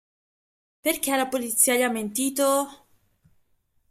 Read more (archaic, feminine) polity (feminine) police Frequency A1 Hyphenated as po‧li‧zì‧a Pronounced as (IPA) /po.litˈt͡si.a/ Etymology From Late Latin polītīa, from Ancient Greek πολιτεία (politeía).